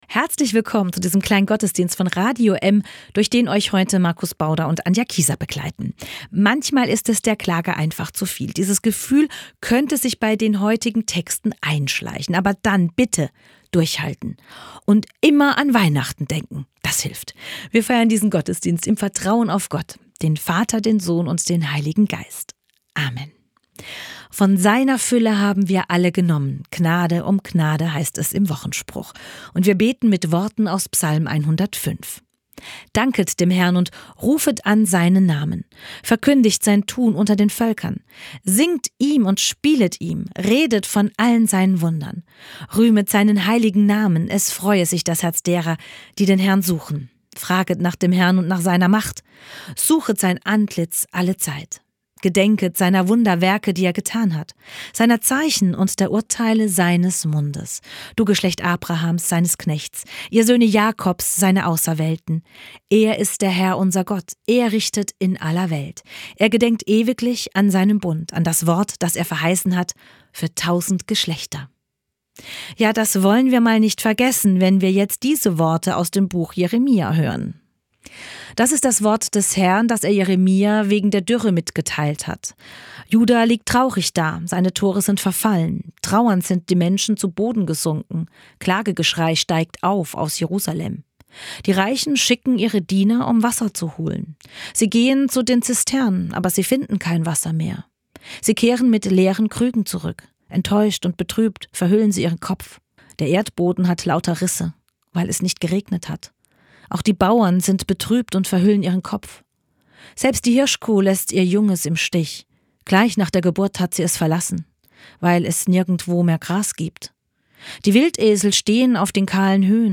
Die Bibel und die Klimakrise: Dürre, menschliche Schuld und Gottes Nähe. Eine Predigt über Verantwortung, Umkehr und Hoffnung in Christus.